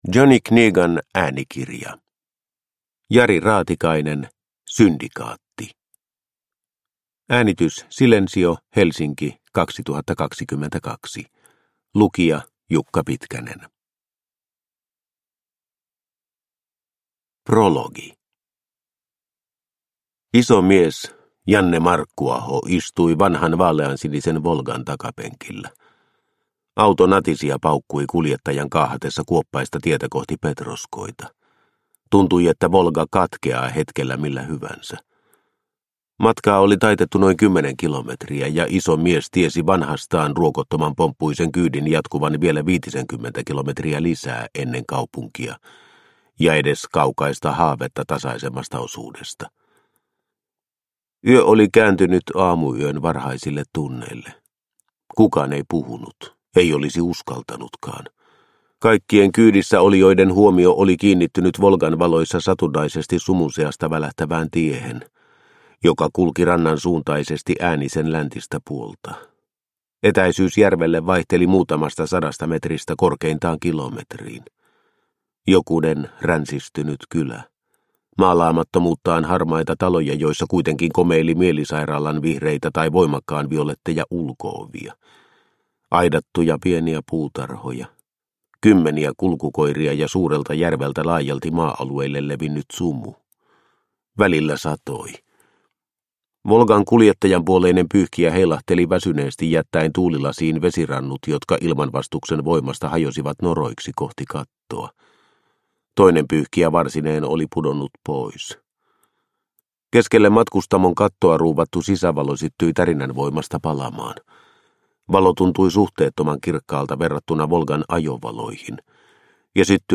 Syndikaatti – Ljudbok – Laddas ner